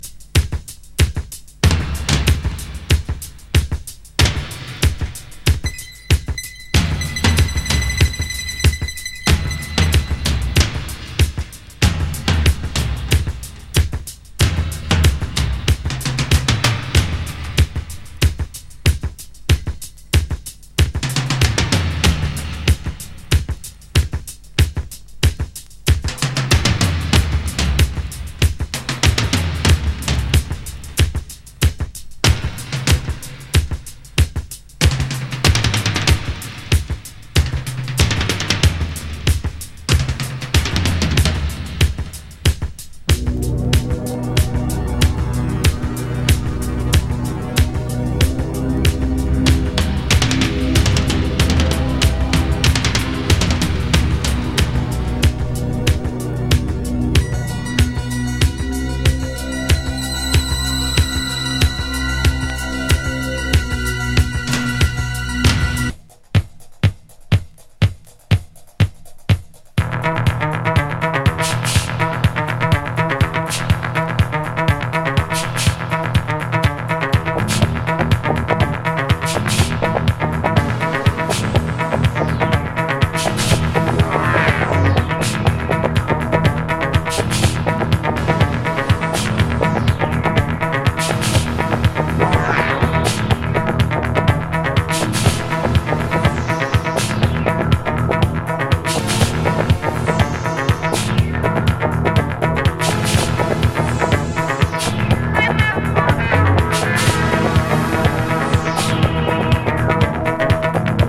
ディープ・コズミック
強烈スペーシー・グルーブ